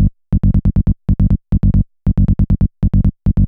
GROOVE BAS-L.wav